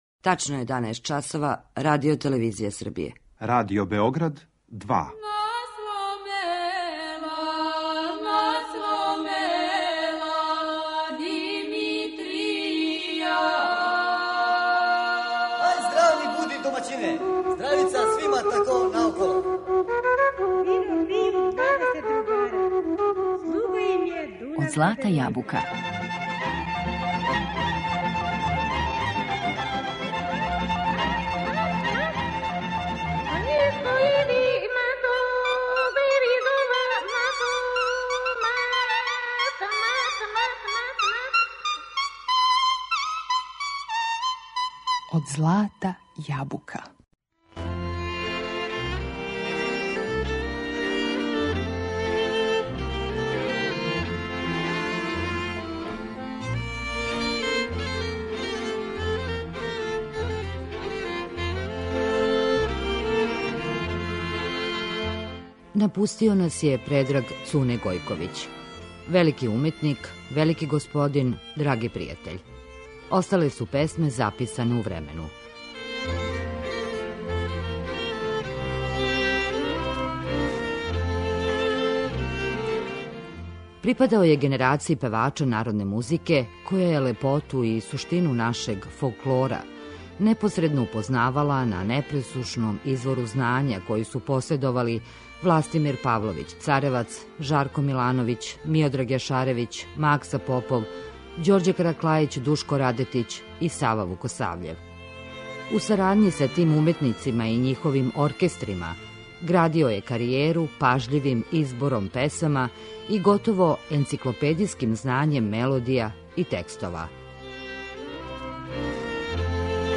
У знак сећања и дубоког поштовања, у две емисије Од злата јабука, данас и сутра, слушаћете разговор који смо забележили са уметником Предрагом Цунетом Гојковићем 2002. године.